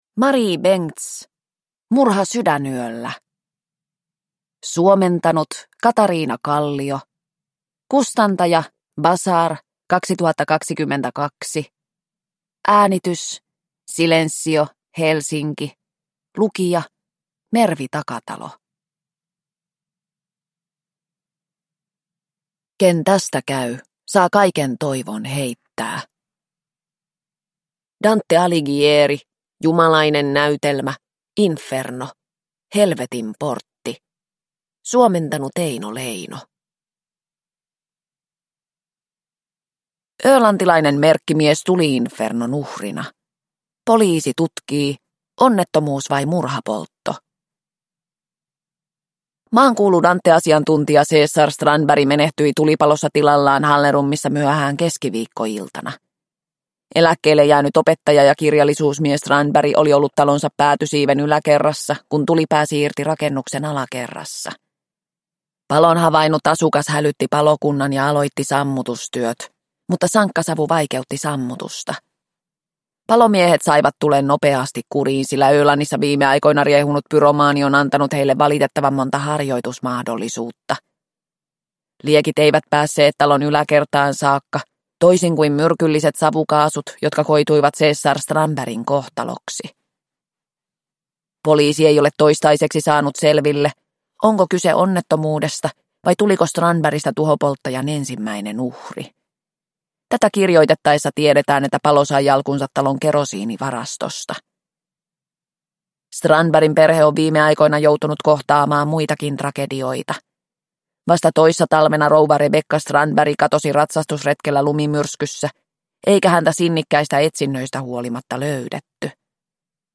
Murha sydänyöllä – Ljudbok – Laddas ner